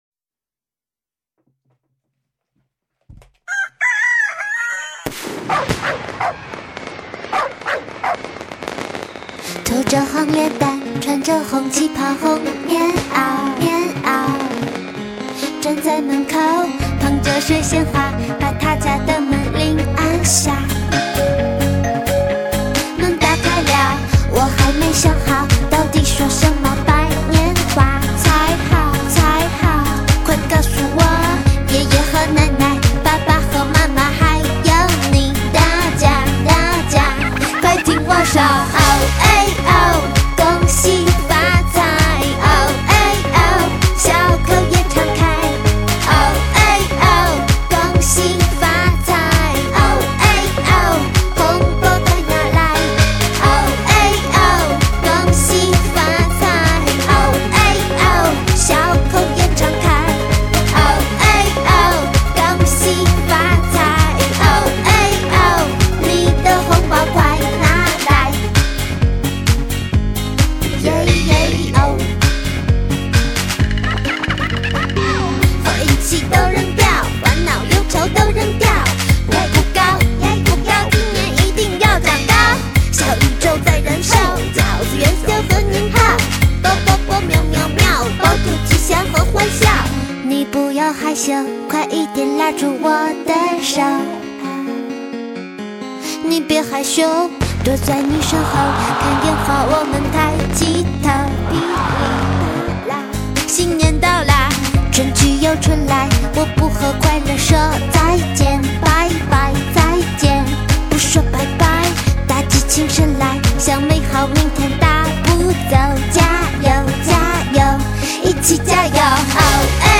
凭借甜美可爱的声音和时尚好听的音乐
用幽默搞笑的歌词、丰富的音效，热热闹闹迎接新年。
在保持传统节日歌曲气氛外，加入了更多时髦的旋律和电子音色。